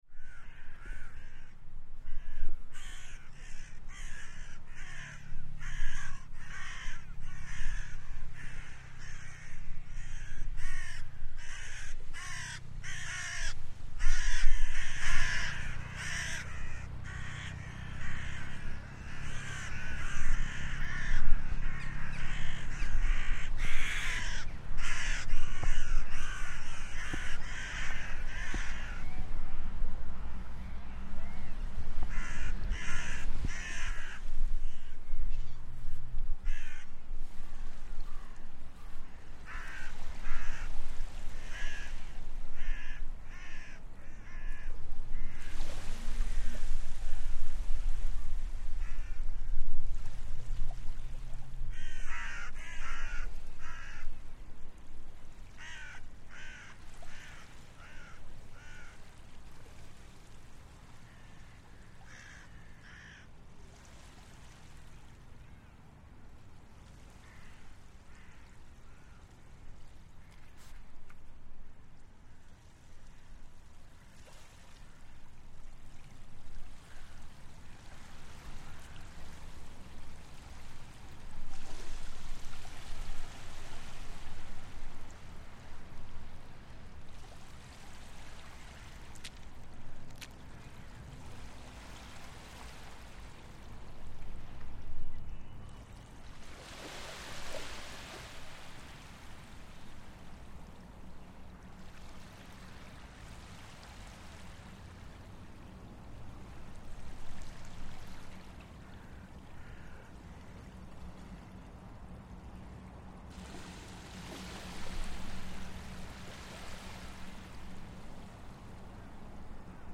Angry jackdaws by the sea
Headliner Embed Embed code See more options Share Facebook X Subscribe A soundscape from Russalka beach, Tallinn, as jackdaws angry at us walking through their low tide feeding area circle above us angrily. We then listen to waves gently breaking and head back up the beach to the main road, as the sounds of nature give way to those of vehicle noise and pollution.